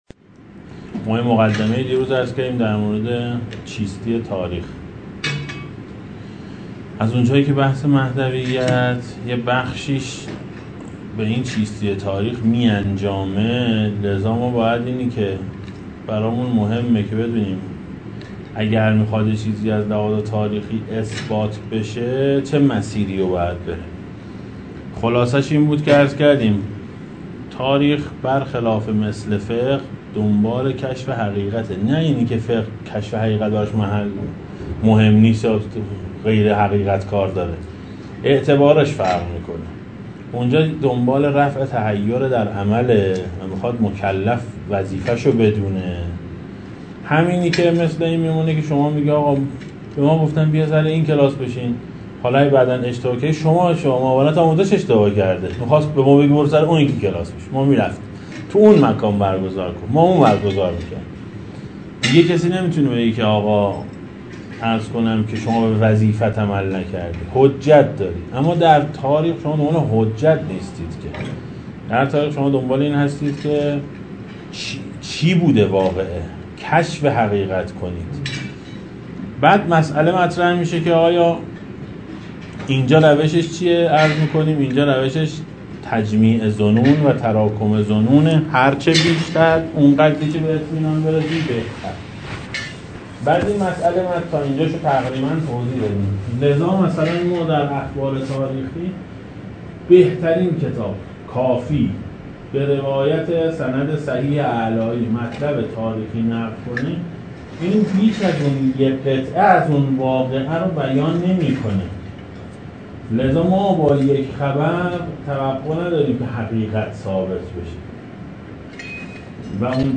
درس مهدویت – جلسه دوم